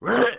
client / bin / pack / Sound / sound / monster / thief1 / damage_4.wav
damage_4.wav